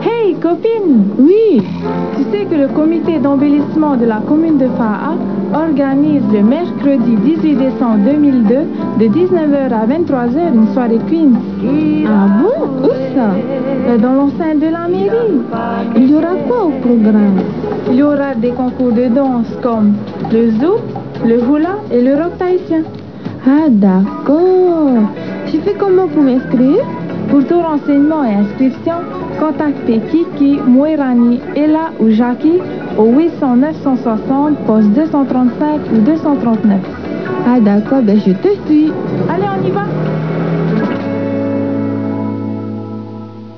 C'est l'histoire de deux copines qui se rencontrent... attention c'est sérieux, aucune place à l'improvisation ! Ces deux-là ont leur texte sous les yeux, pour un peu on entendrait le bruit du papier froissé...